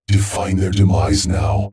Subject description: A set of voice with metallic feel   Reply with quote  Mark this post and the followings unread